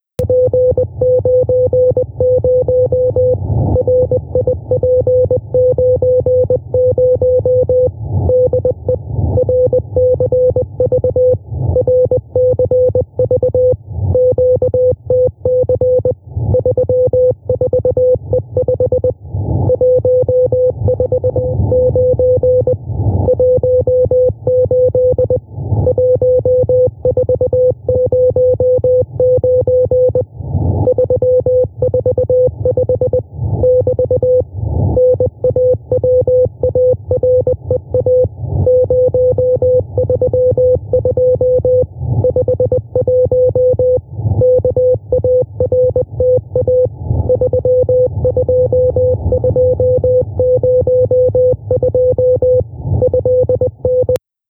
They are signals transmitted in Morse code.
Below is an example of intrusion on the HF amateur bands using the CW — A1A transmission mode .
CW-A1A-RCV.wav